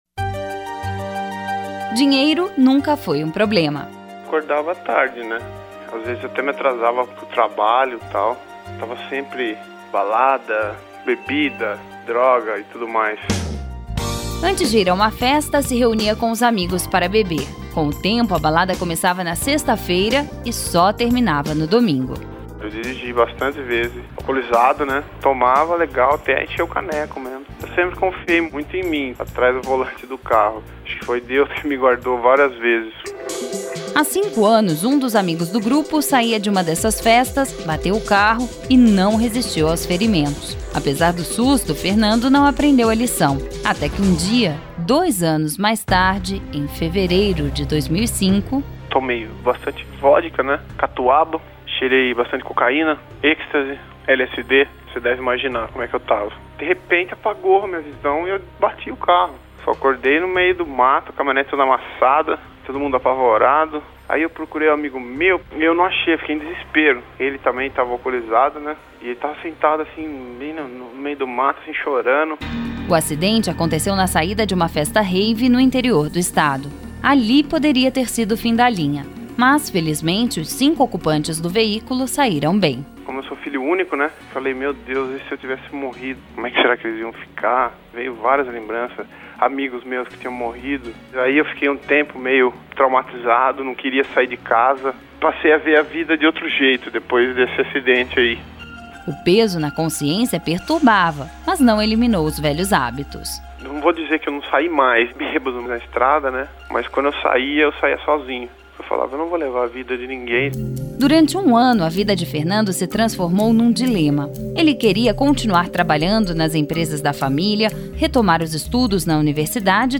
A Confederação Nacional do Transporte escolheu a série especial como o melhor produto veiculado pelo rádio brasileiro em 2008.